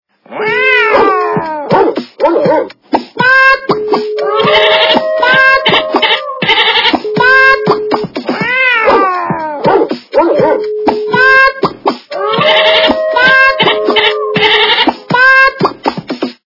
Природа животные